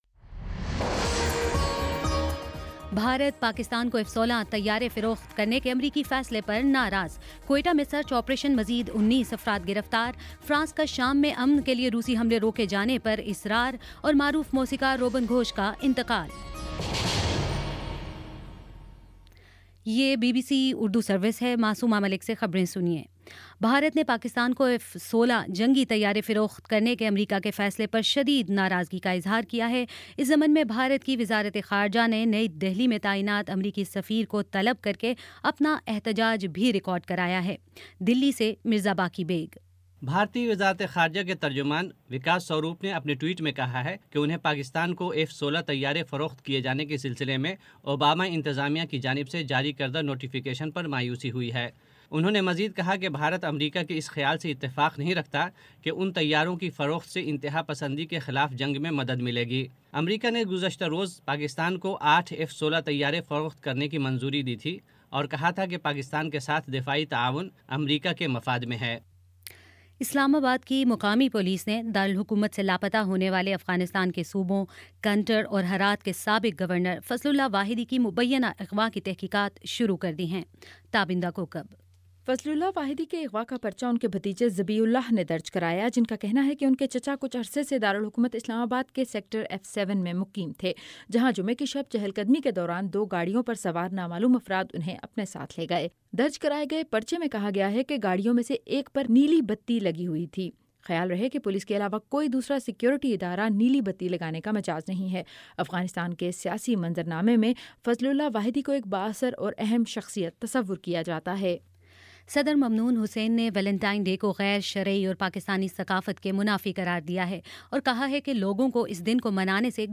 فروری 13 : شام پانچ بجے کا نیوز بُلیٹن